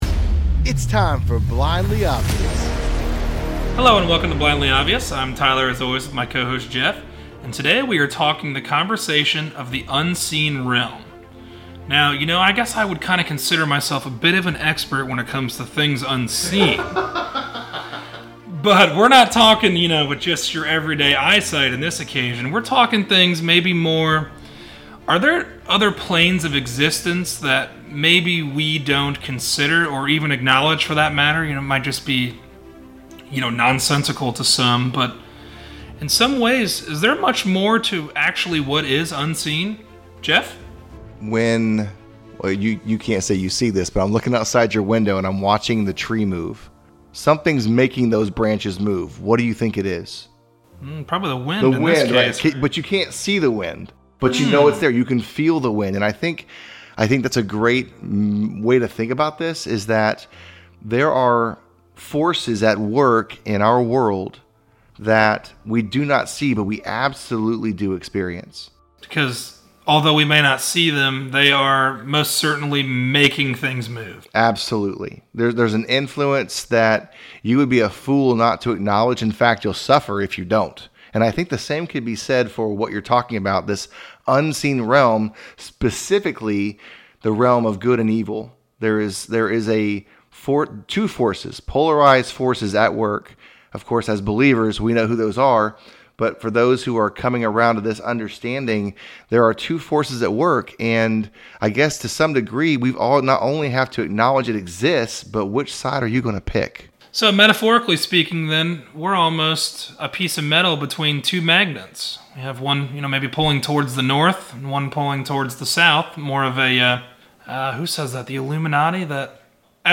A conversation about the unseen realm of existence. Many solely shape their reality based upon what their eyes tell them, but are there things unseen, working in the background to shape our existence much more than we consider?